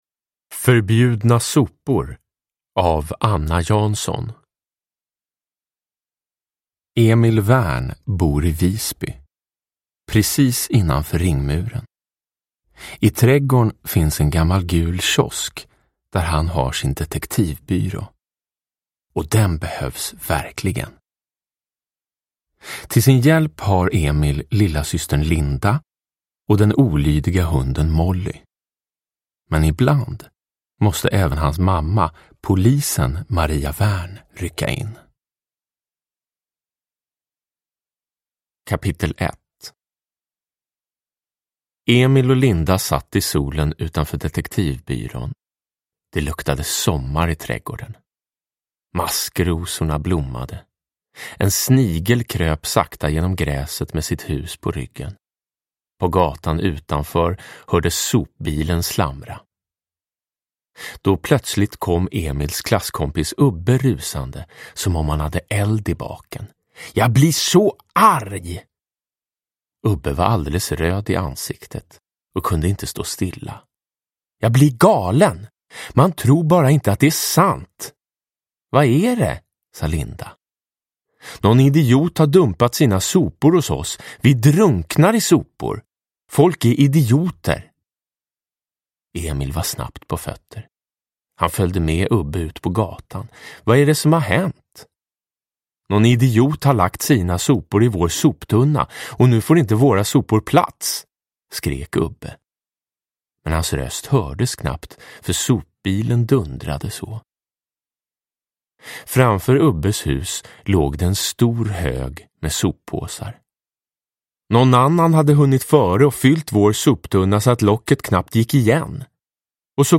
Förbjudna sopor – Ljudbok – Laddas ner
Uppläsare: Jonas Karlsson